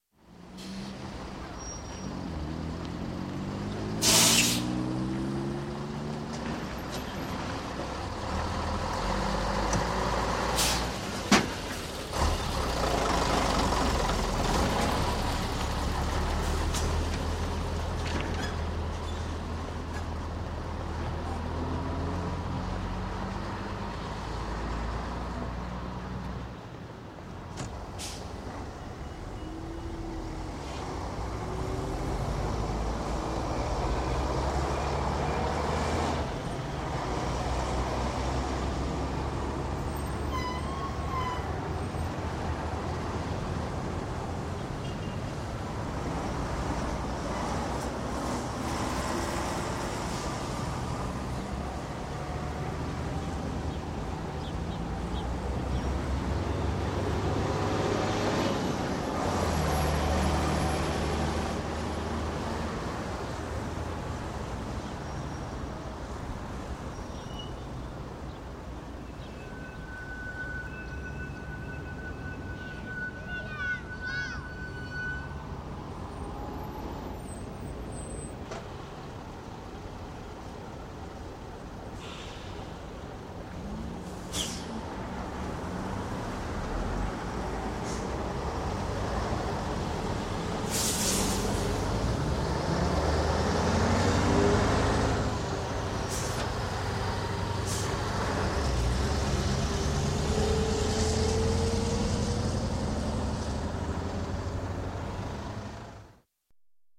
Звуки автовокзала
Шум автостанции без разговоров